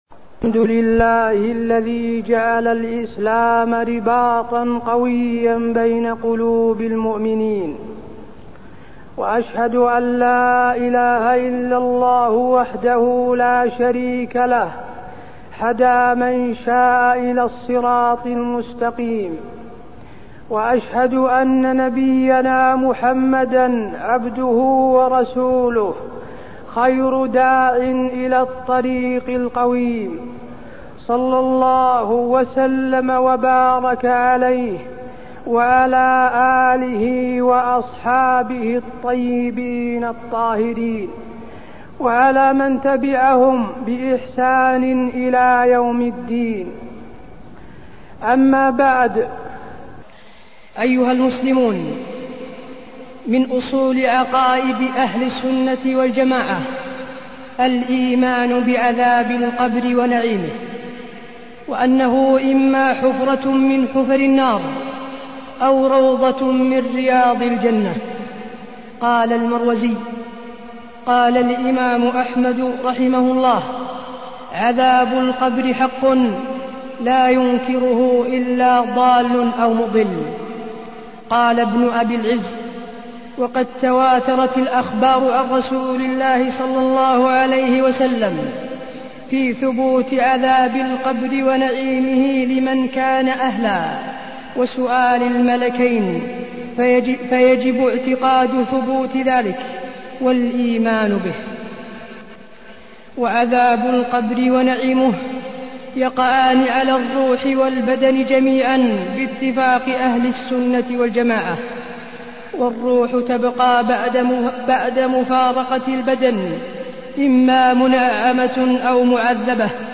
تاريخ النشر ١٠ ربيع الثاني ١٤٢٠ هـ المكان: المسجد النبوي الشيخ: فضيلة الشيخ د. حسين بن عبدالعزيز آل الشيخ فضيلة الشيخ د. حسين بن عبدالعزيز آل الشيخ عذاب القبر